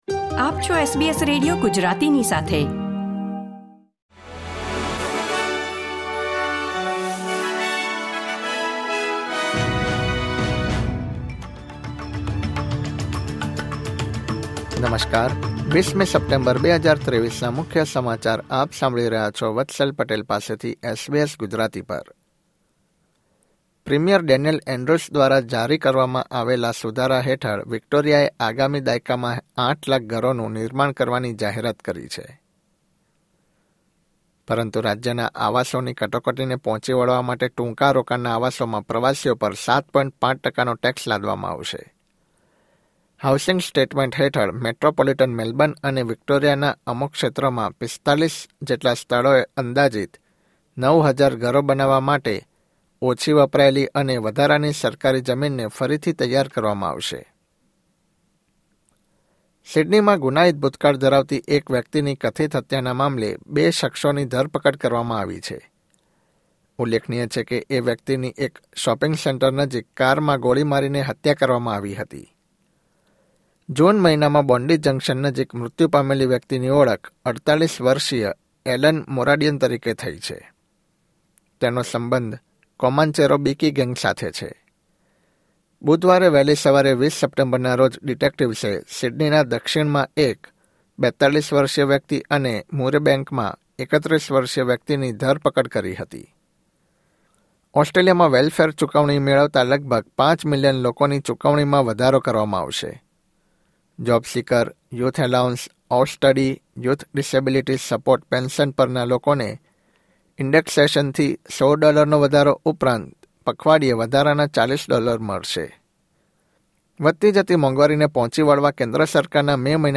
SBS Gujarati News Bulletin 20 September 2023